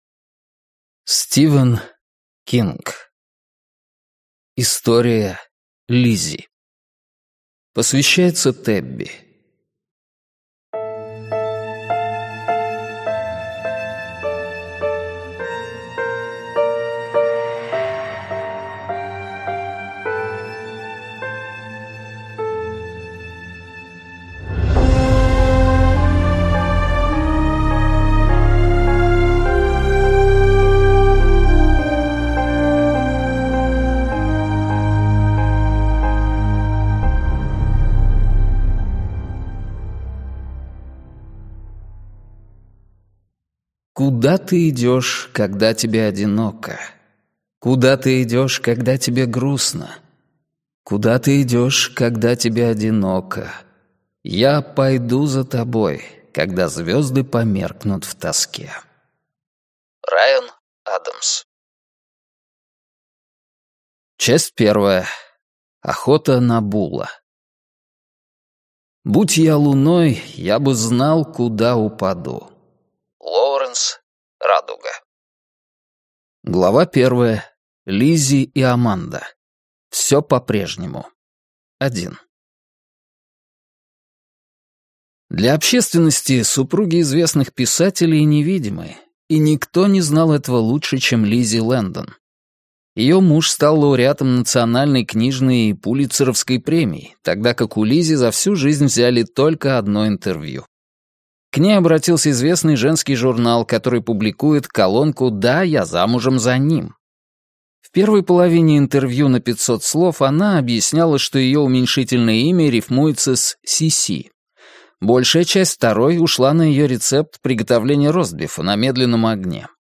Аудиокнига История Лизи - купить, скачать и слушать онлайн | КнигоПоиск